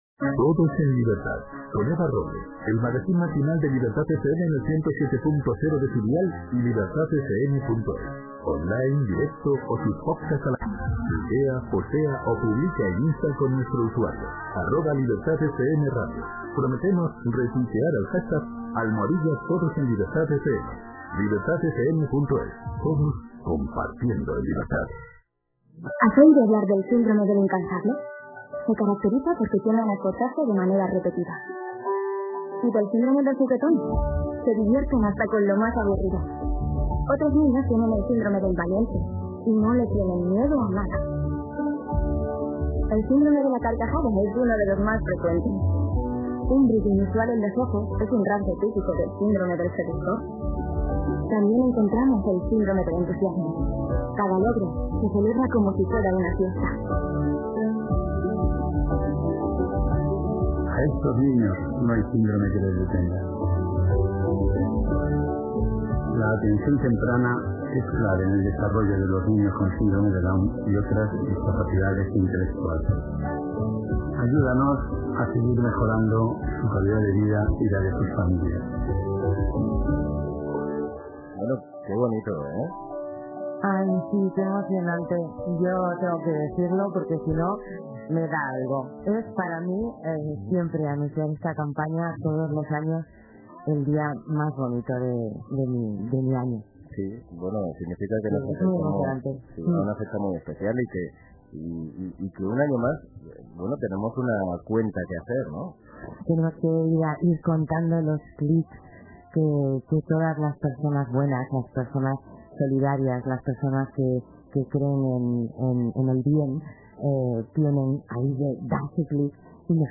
Entrevista
Entrevista en Todos en Libertad